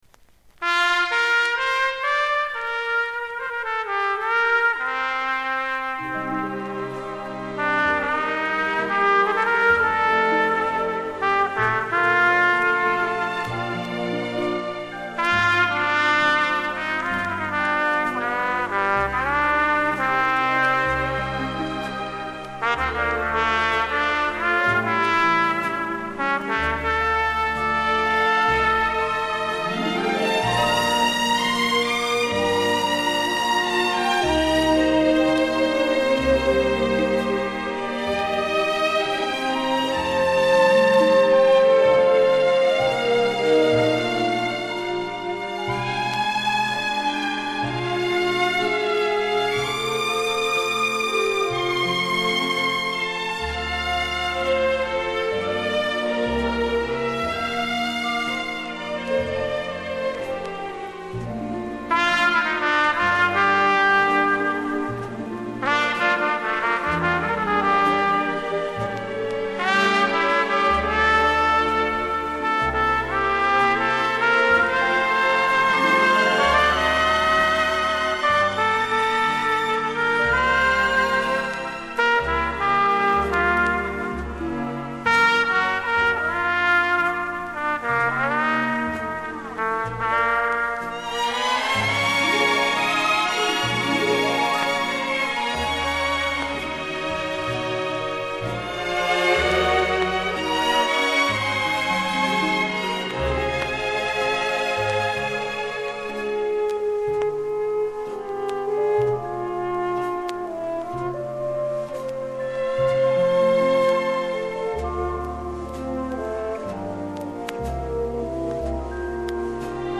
в стиле "ballada" со струнным оркестром